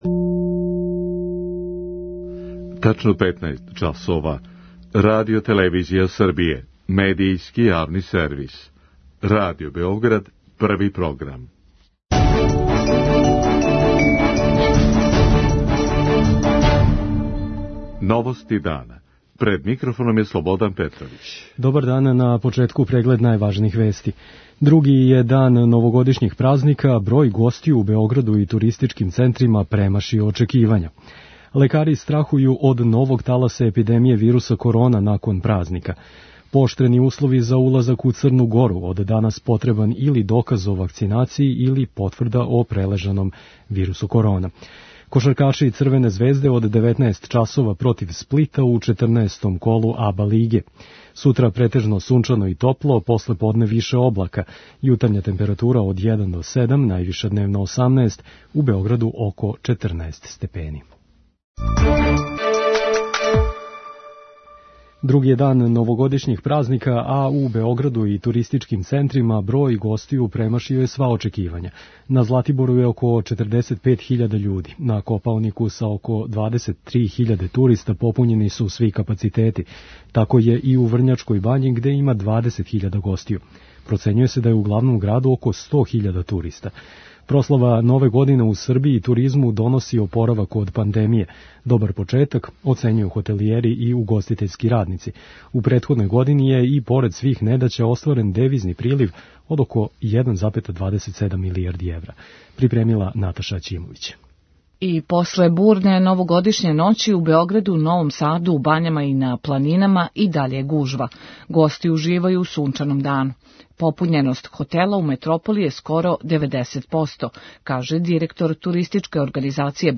Процењује се да у Београду борави више од 100 000 гостију. преузми : 6.30 MB Новости дана Autor: Радио Београд 1 “Новости дана”, централна информативна емисија Првог програма Радио Београда емитује се од јесени 1958. године.